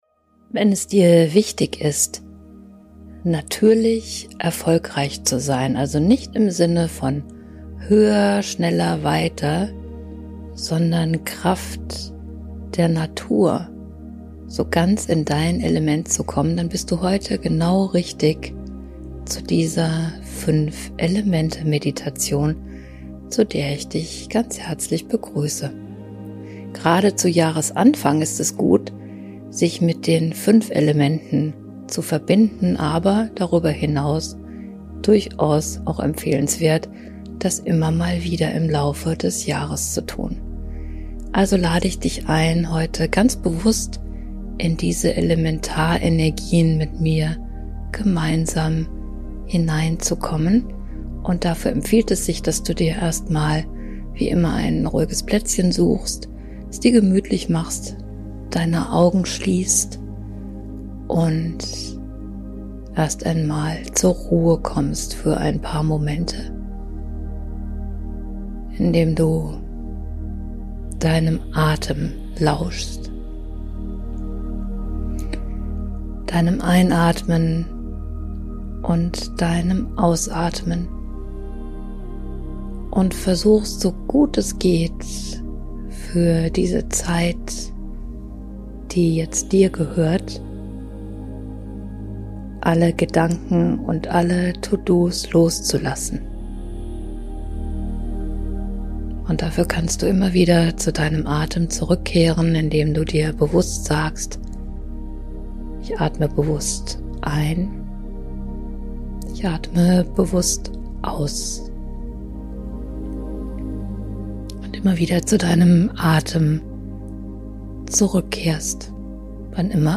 In dieser 5-Elemente-Meditation lade ich dich ein, dich als Mittelpunkt deines ganz persönlichen Jahreskompasses zu erleben. Du verbindest dich mit den Himmelsrichtungen, mit den Jahreszeiten und mit den elementaren Kräften, aus denen wir schöpfen – jeden Moment, ganz selbstverständlich. Höre diese Meditation am besten mit Kopfhörern und schenke dir diesen Raum ganz für dich.